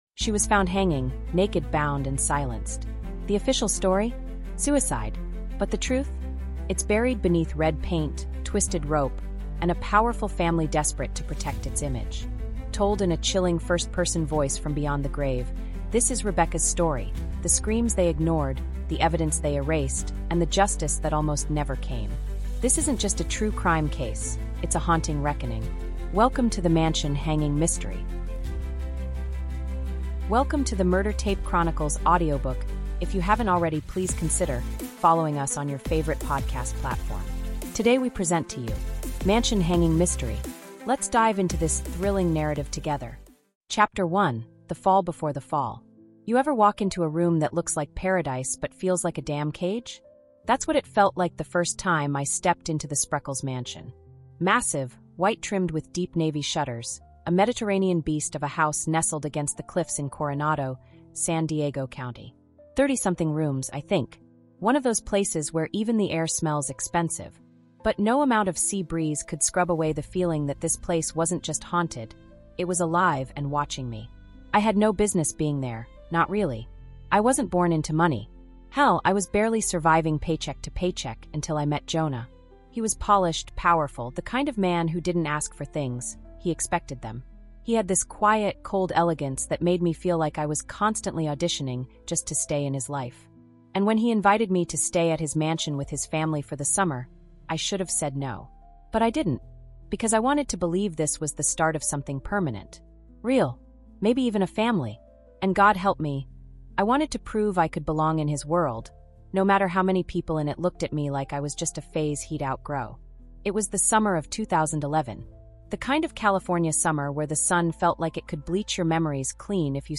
This 5-part true crime audiobook delves deep into the chilling details of the case, unraveling a web of conspiracy, scandal, and corruption that continues to baffle investigators and the public alike.